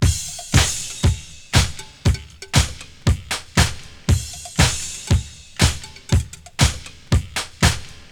Tuned drums (F key) Free sound effects and audio clips
• 118 Bpm Drum Beat F Key.wav
Free drum loop - kick tuned to the F note. Loudest frequency: 2434Hz
118-bpm-drum-beat-f-key-LN8.wav